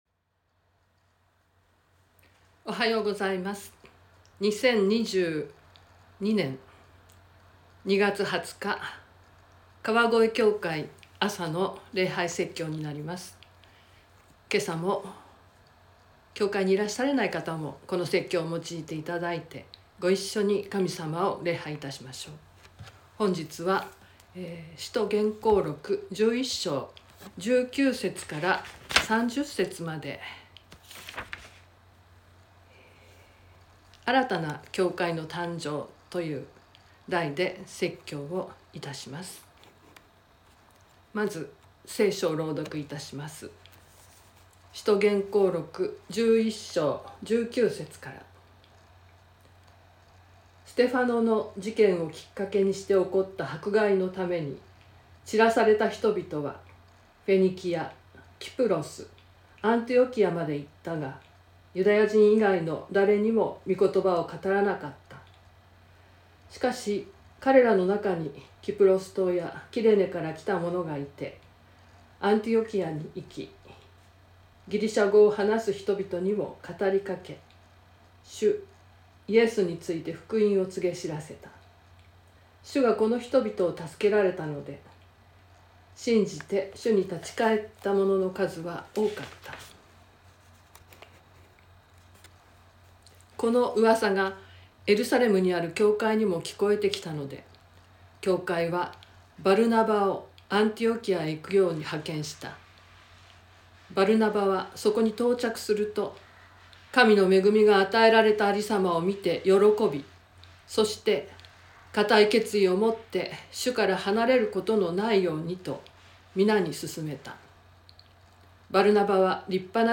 川越教会。説教アーカイブ。
音声ファイル 礼拝説教を録音した音声ファイルを公開しています。